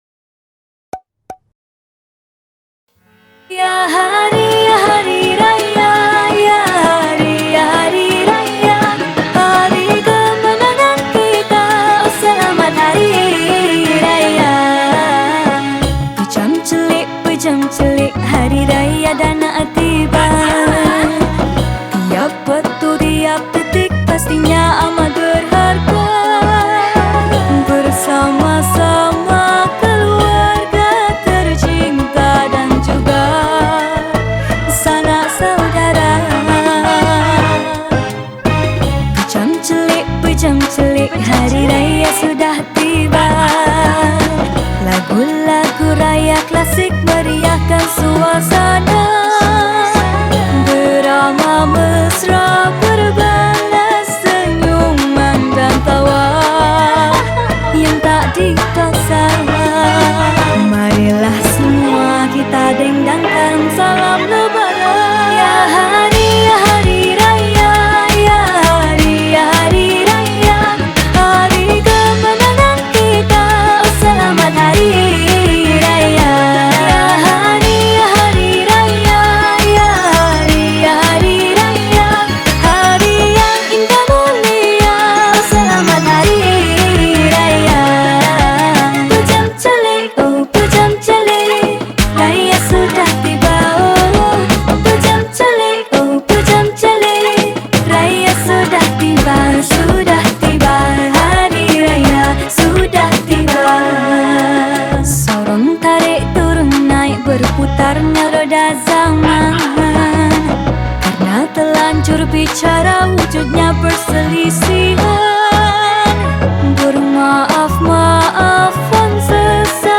Lagu Hari Raya
Malay Song